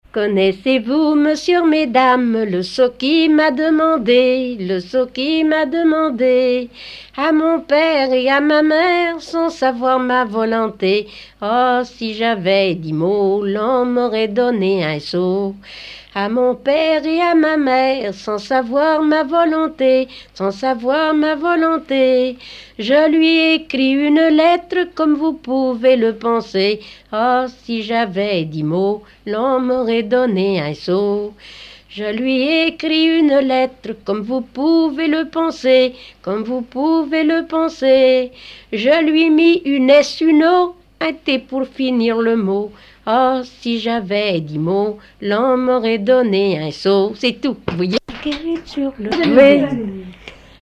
Barbâtre ( Plus d'informations sur Wikipedia ) Vendée
danse : ronde
Genre laisse
Pièce musicale inédite